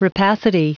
Prononciation du mot rapacity en anglais (fichier audio)
Prononciation du mot : rapacity